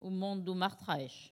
Collectif-Patois (atlas linguistique n°52)
Locution